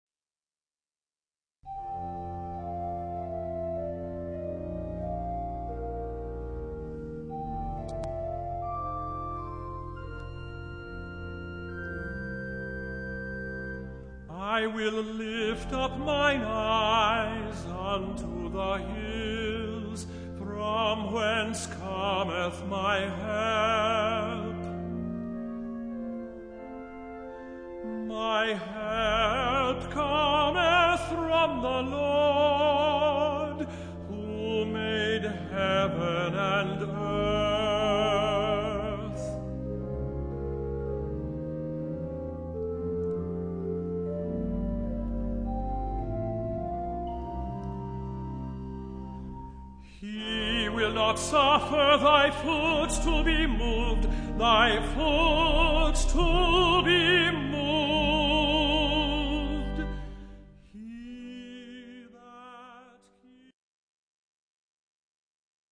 Compositions for Solo Voice